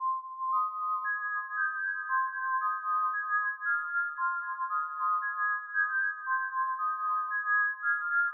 令人毛骨悚然的邪恶恶魔怪物咆哮的万圣节
我非常感激 这是我用我的声音创建的效果，使用我的Audio Technica麦克风在Audacity中创建恶魔咆哮。我想创造一种令人毛骨悚然的声音，但对于孩子或孩子的故事来说并不太可怕。